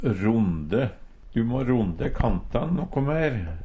Tilleggsopplysningar ronde: kan og gå på folk som pratar og aldri kjem til poenget Høyr på uttala Ordklasse: Verb Attende til søk